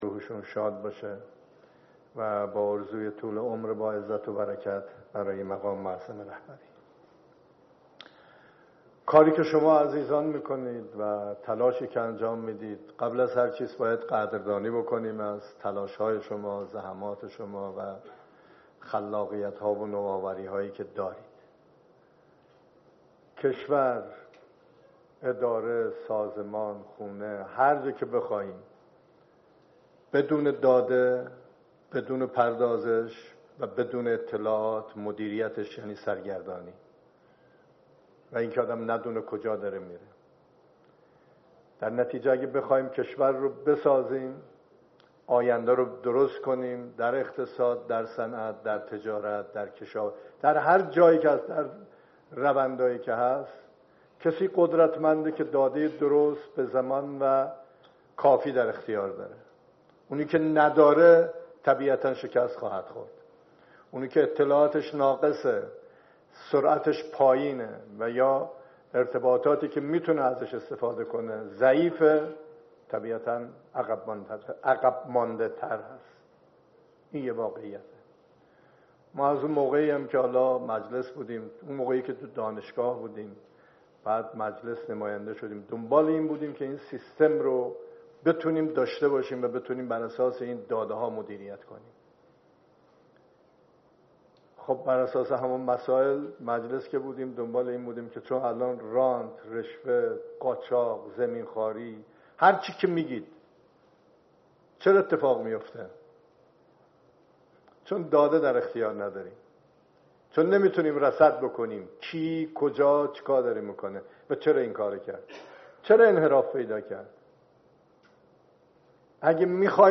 سخنان رییس جمهور در آیین افتتاح طرح های ارتباطات و فناوری اطلاعات